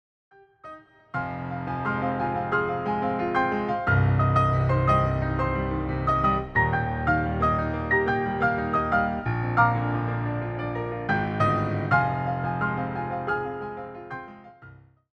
translate to a solo piano setting.